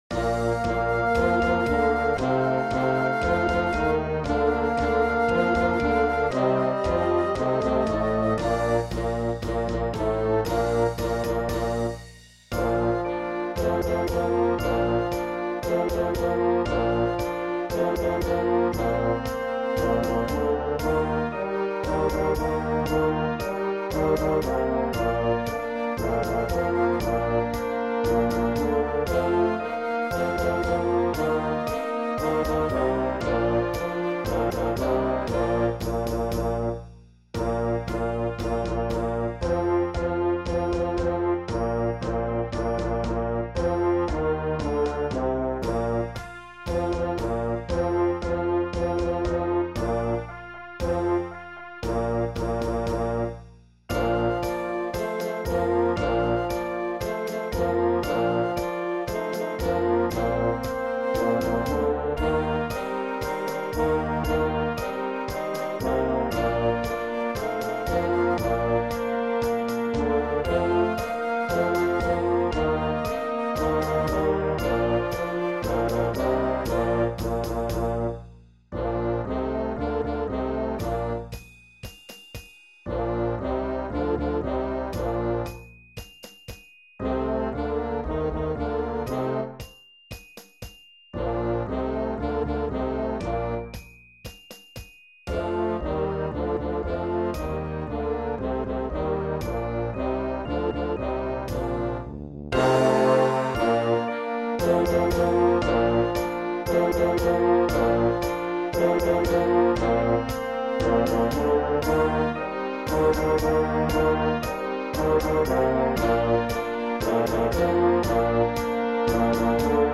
Beginner Concert Band
MP3 Computer generated file      Youtube link
Section D is a percussion feature.
Dynamics - Fortissimo, Forte, Mezzo Forte, Piano, Crescendo.
Form - Intro, A, B, A, C, A Coda.  (Quasi Rondo)